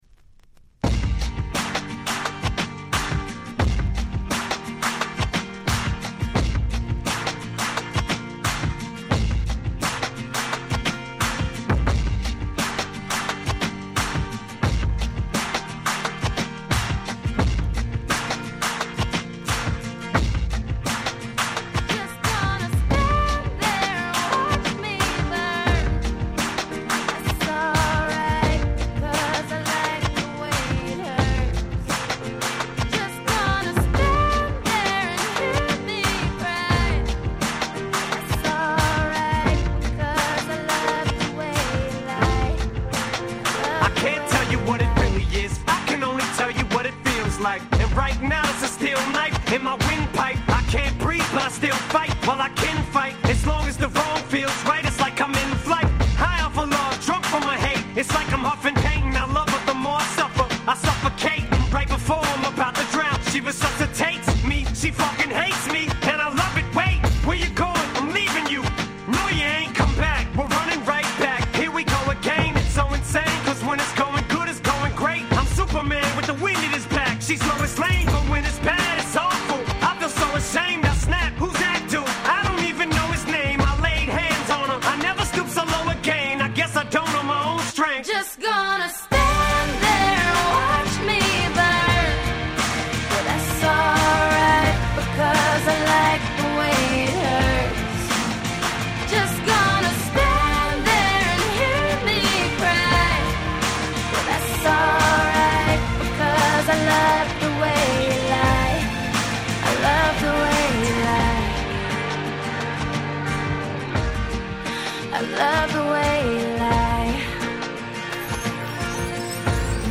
10' Super Hit Hip Hop/R&B !!